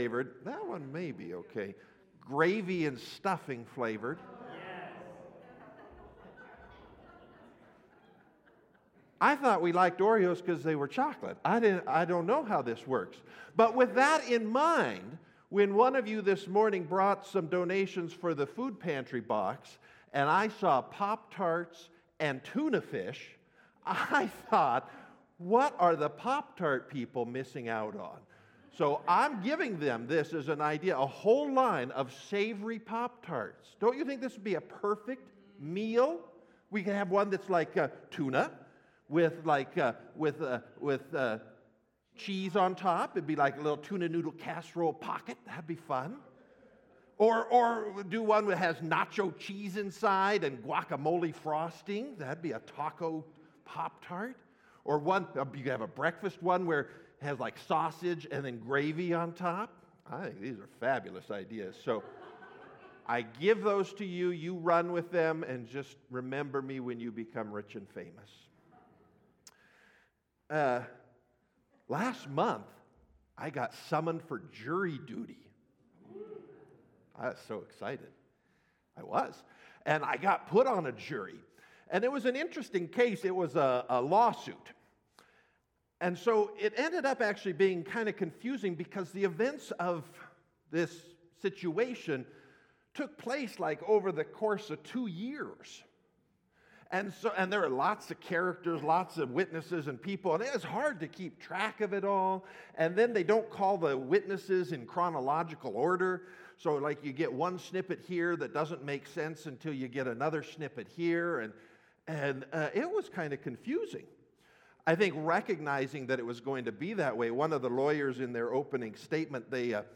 Sermons by First Free Methodist Spokane